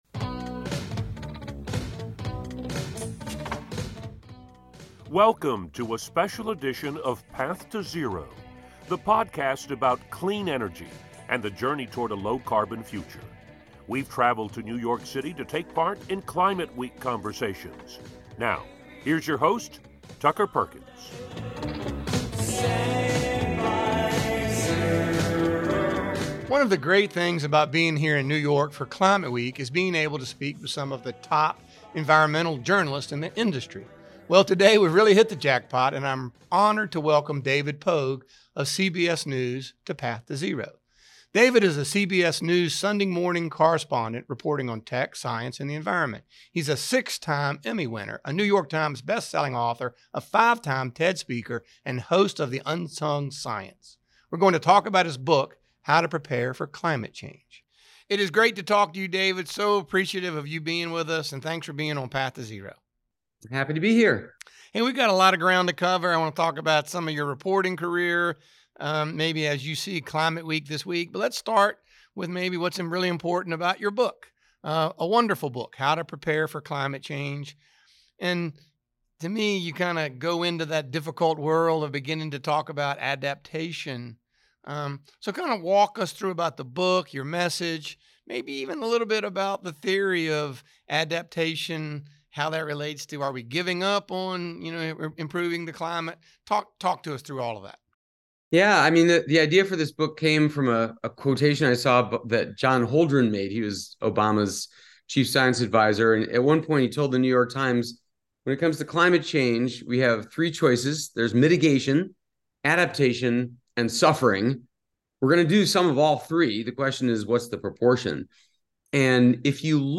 In this episode from Climate Week in New York, you’ll get some practical advice on how to adapt to climate-related extreme weather from David Pogue, New York Times Bestselling selling author and CBS News Correspondent.